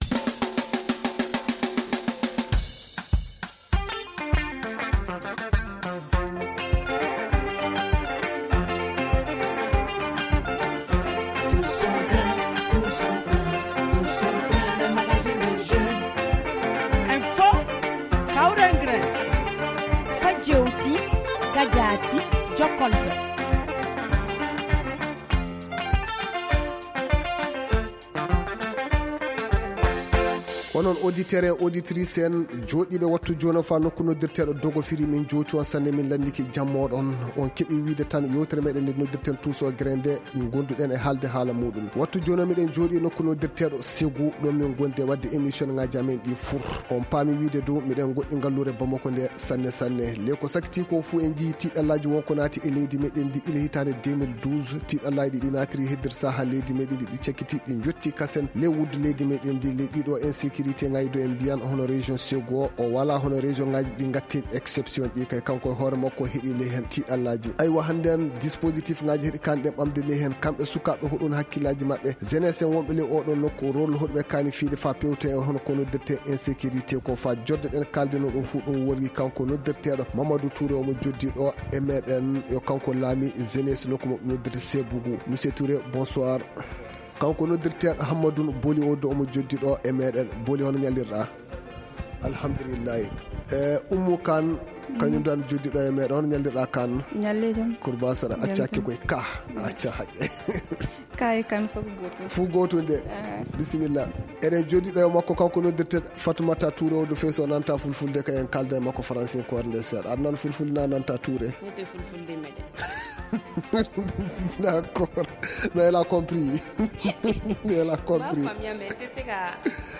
pose le débat à Ségou